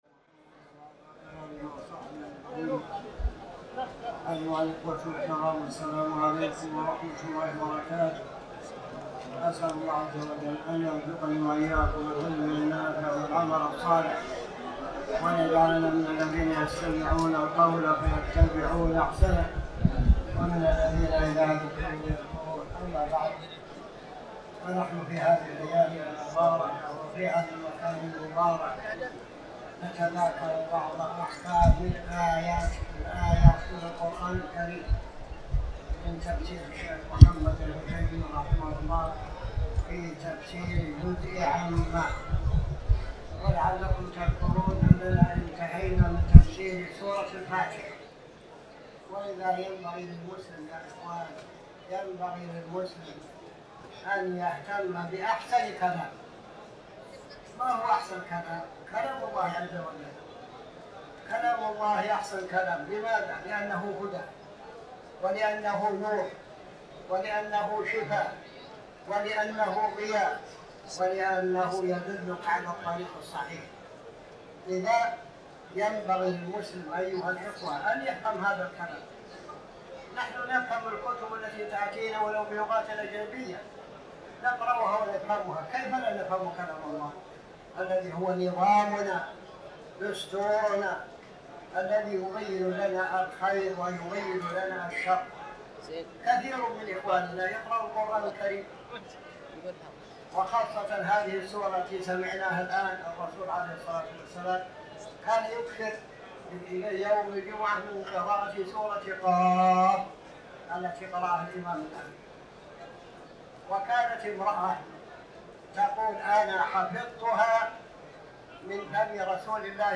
تاريخ النشر ٢٤ رمضان ١٤٤٠ هـ المكان: المسجد الحرام الشيخ